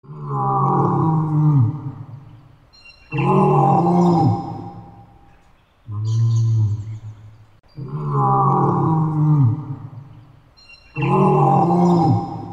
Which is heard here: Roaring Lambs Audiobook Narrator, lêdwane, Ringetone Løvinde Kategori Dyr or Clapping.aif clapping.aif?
Ringetone Løvinde Kategori Dyr